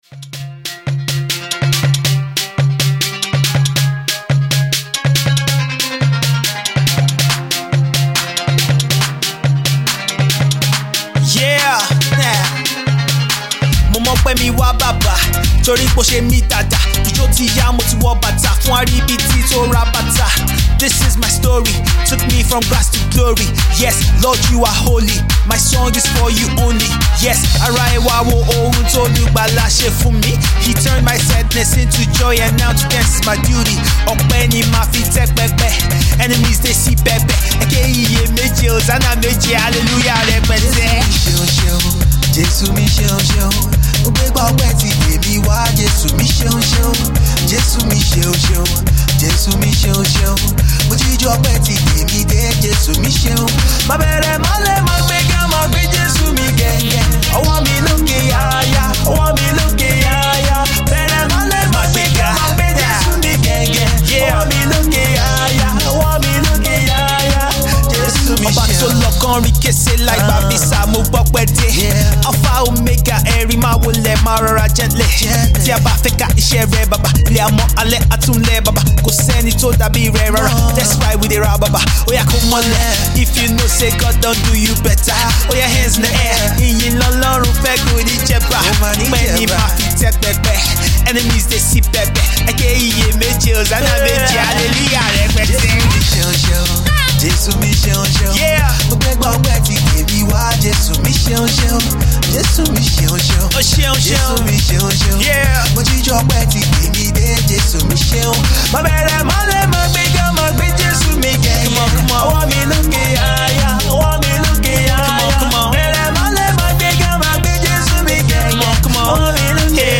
is a budding Gospel artist with his silky smooth vocals